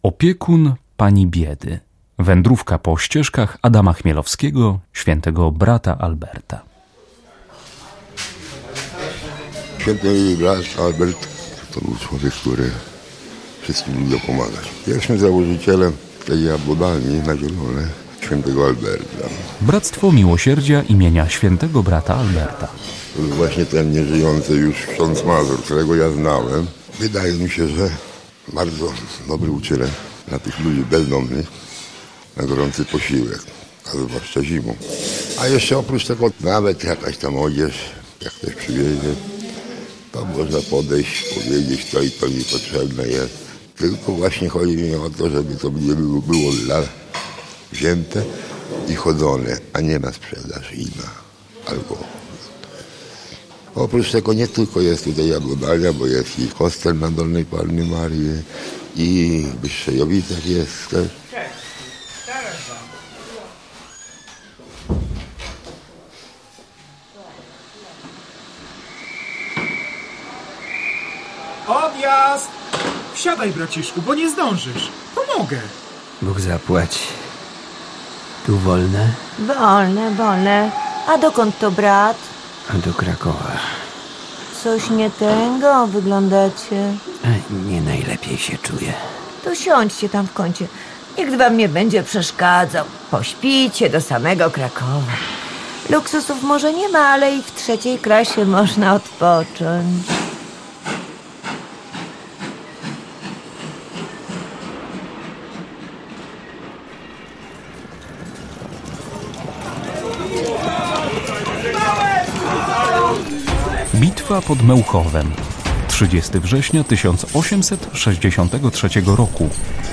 W słuchowisku historia życia św. brata Alberta Chmielowskiego.